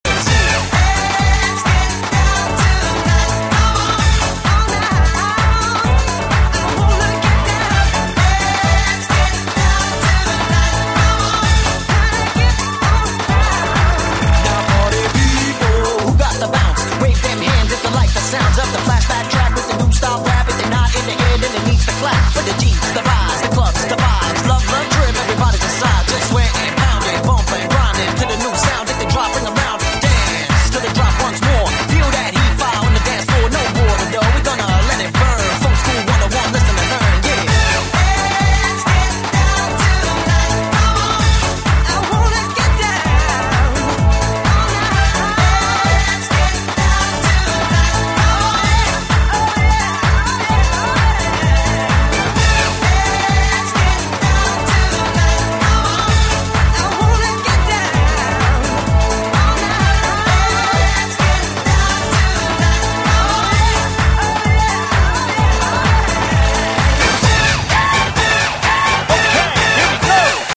BPM128-128
Audio QualityCut From Video